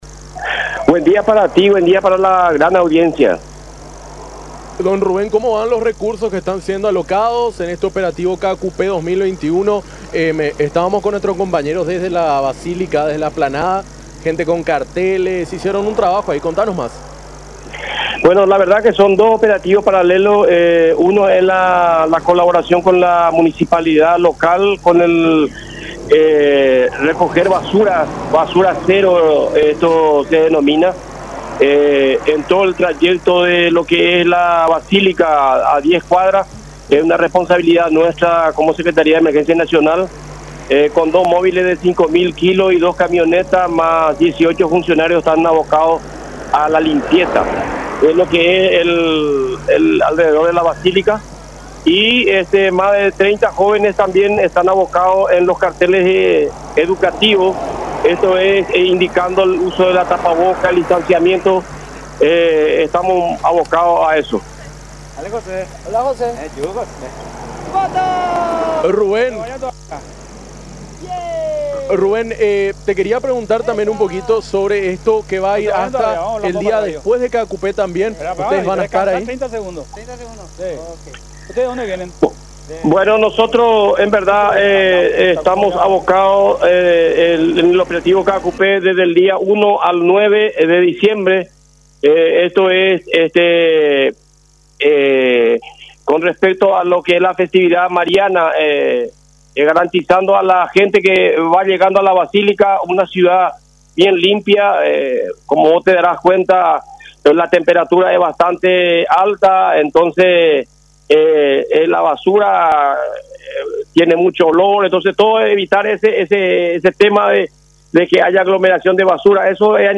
en diálogo con Enfoque 800 por La Unión.